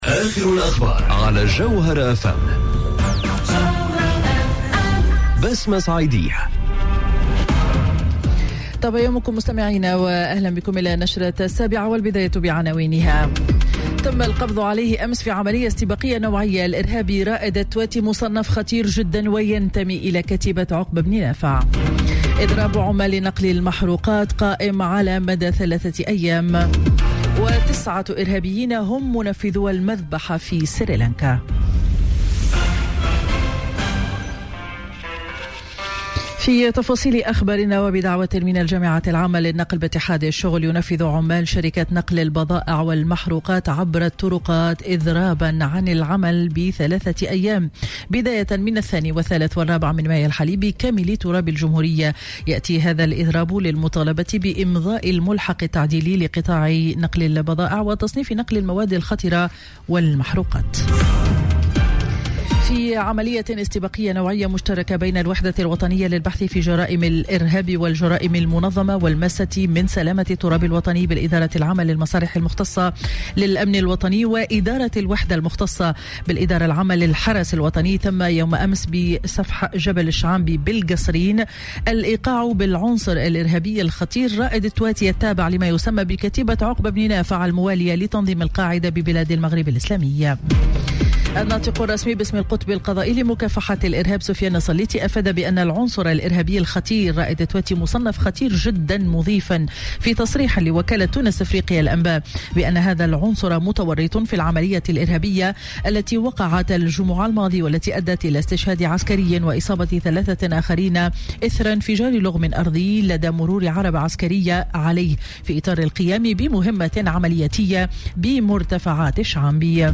نشرة أخبار السابعة صباحا ليوم الخميس 2 ماي 2019